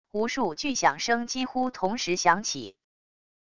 无数巨响声几乎同时响起wav音频